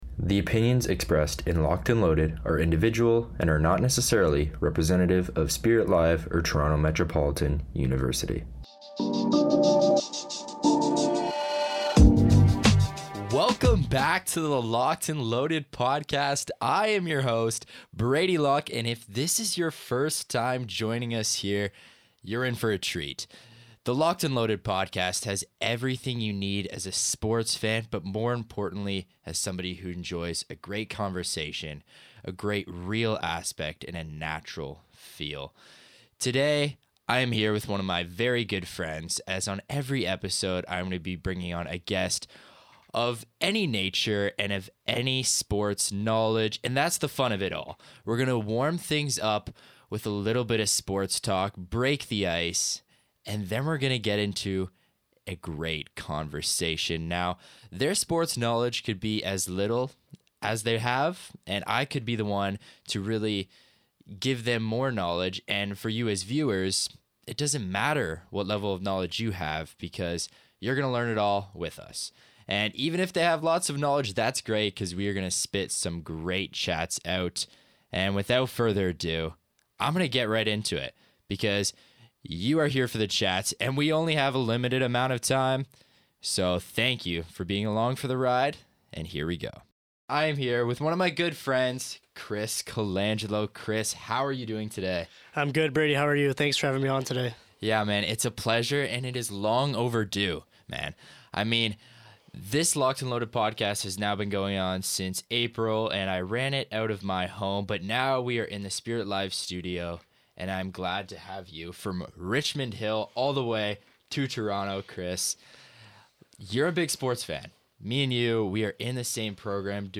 Real conversations. Raw emotions. No scripts.